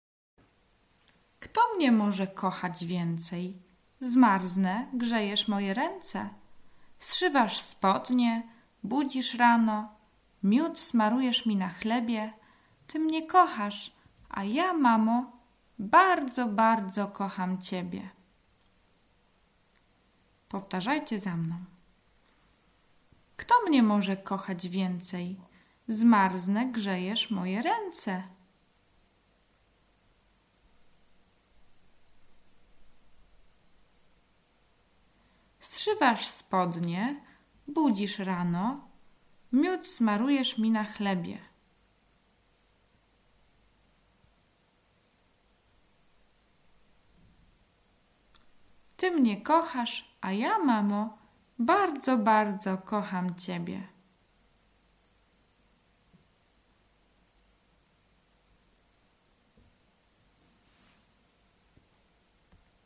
Poniżej znajdują się nagrania trzech krótkich wierszyków wraz z pauzami do nauki i powtarzania przez dziecko.